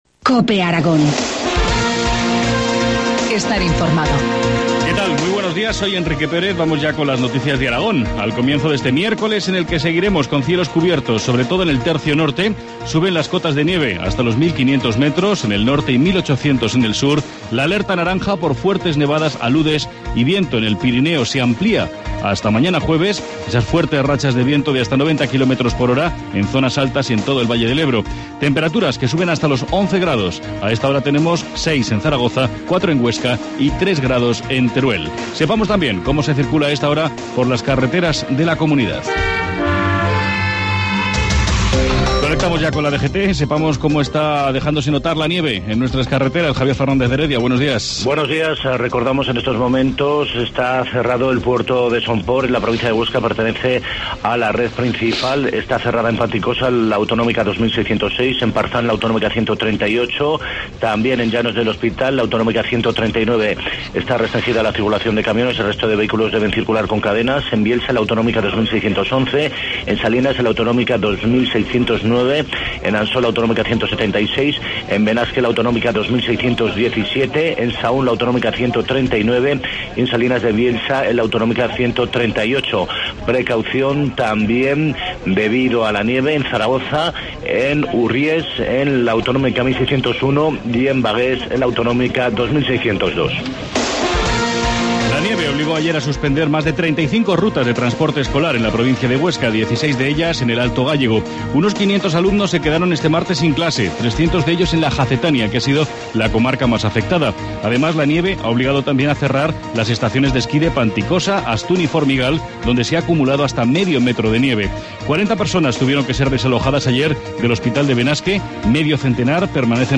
Informativo matinal, miércoles 16 de enero, 7.25 horas